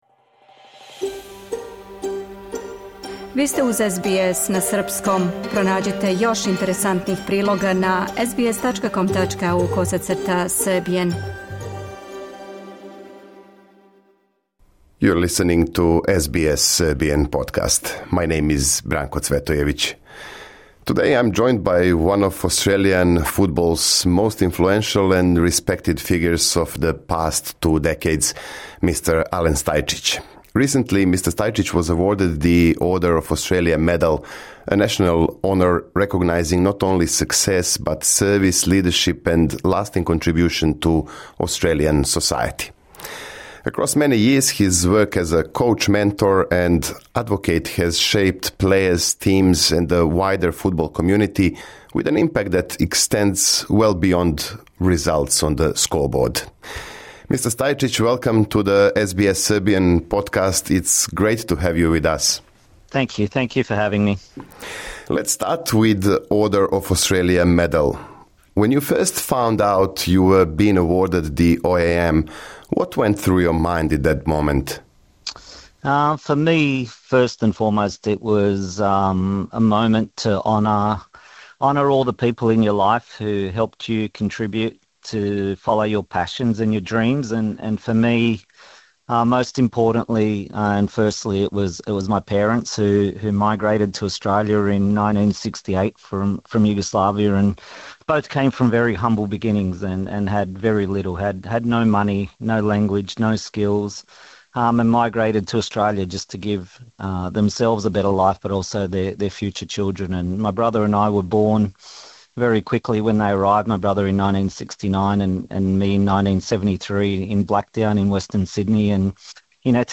Познати тренер је поводом Дана Аустралије одликован једним од највећих националних признања, чиме је награђен за допринос и достигнућа у фудбалу. У ексклузивном интервјуу за СБС на српском, некадашњи селектор женске репрезентације је у први план истакао допринос који су у његовом животу и каријери дали пре свега најближи - родитељи, супруга и деца. Стајчић се присетио и одрастања у Западном Сиднеју, играња фудбала и фолклора у српској цркви у Рути Хилу, као и година које је провео у Белим орловима из Бонирига.